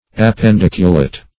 Search Result for " appendiculate" : The Collaborative International Dictionary of English v.0.48: Appendiculate \Ap`pen*dic"u*late\, a. [See Appendicle .]
appendiculate.mp3